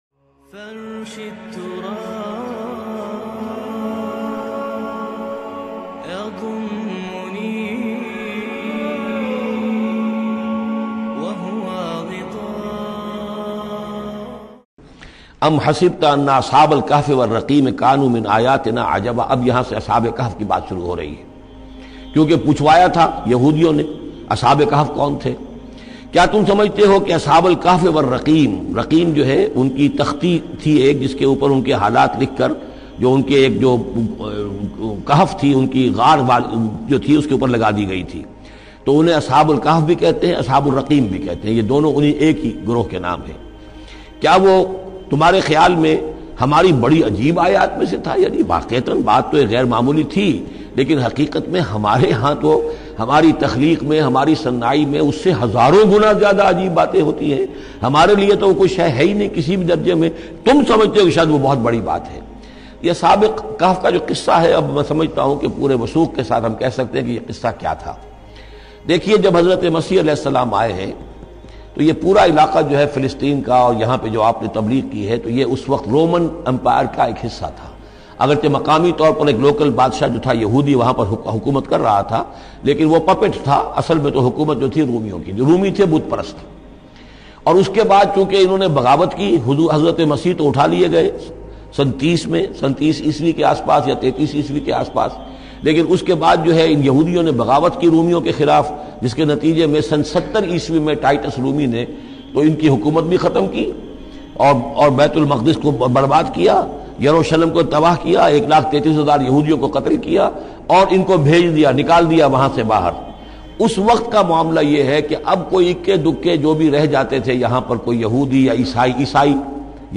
Ashab e khaf Ka Waqia By Dr Israr Ahmed Bayan MP3 Download